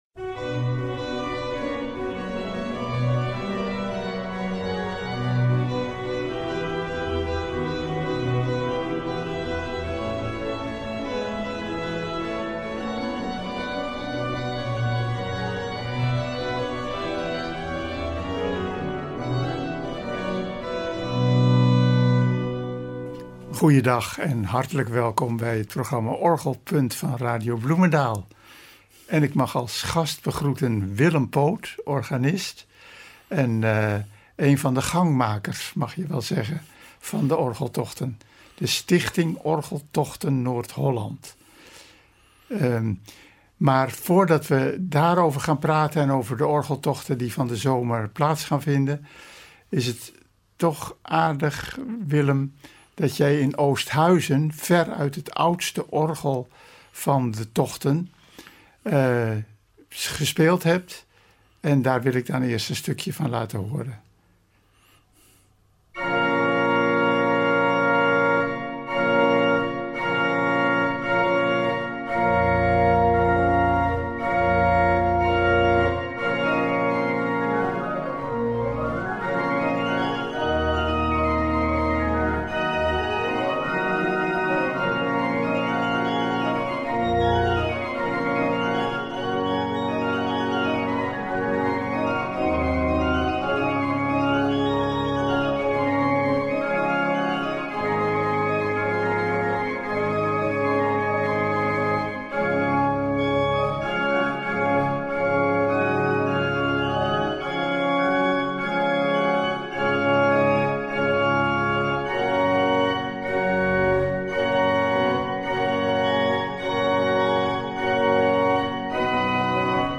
Van veel andere orgels is ook een opname in de uitzending te horen.